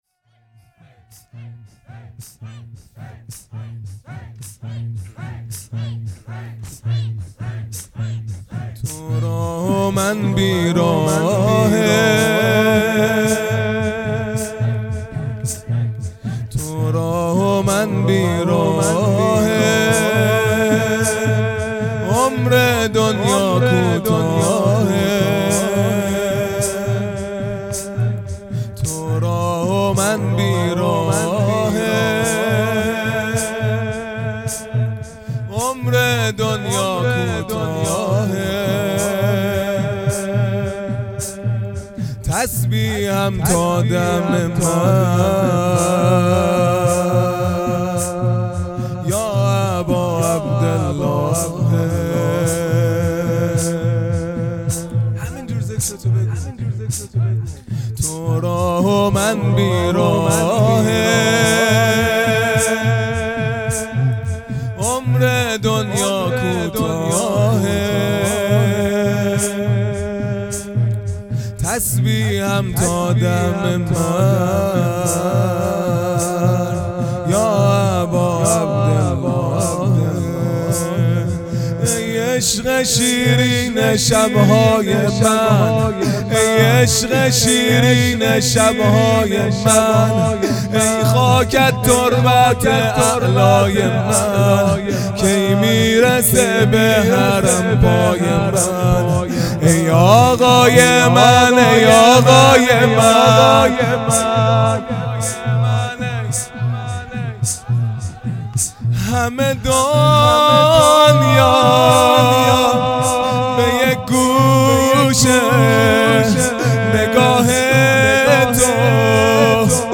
خیمه گاه - هیئت بچه های فاطمه (س) - زمینه | تو راه و من بیراهه
دهه اول محرم الحرام ۱۴۴٢